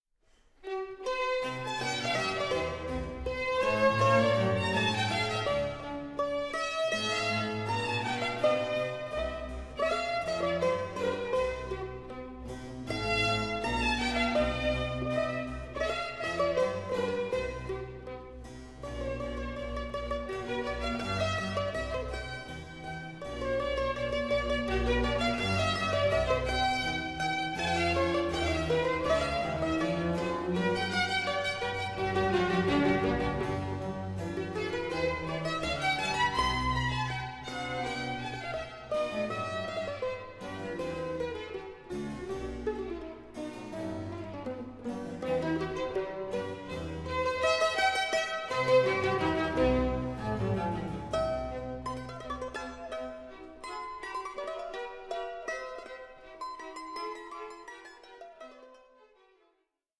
works for mandolin and orchestra